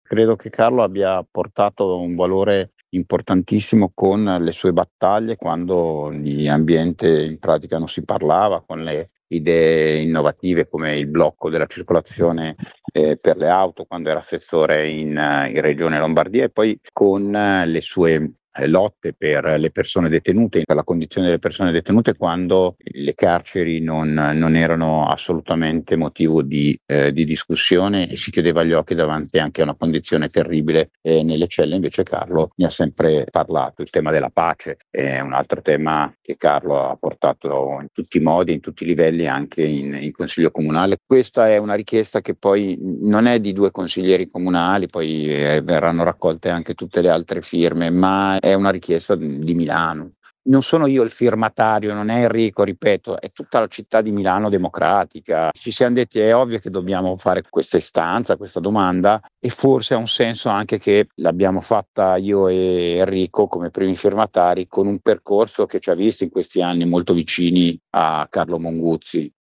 Sentiamo perché, secondo Alessandro Giungi: